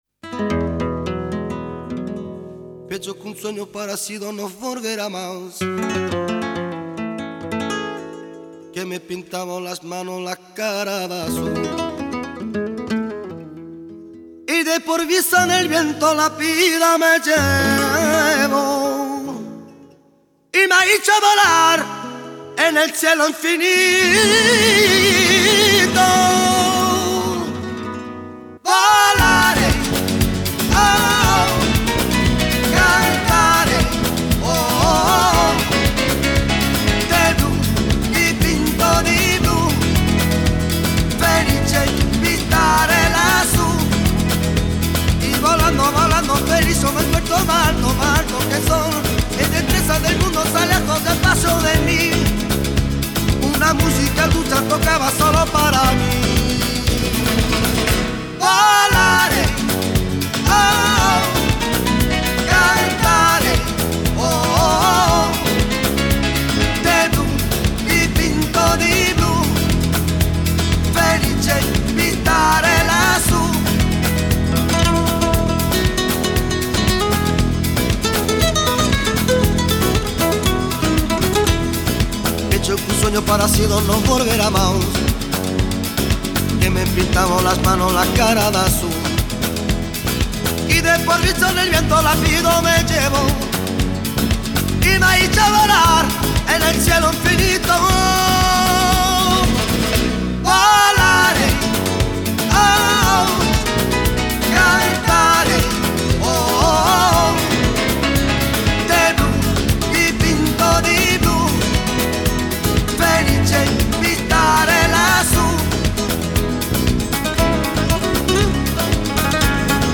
Латиноамериканская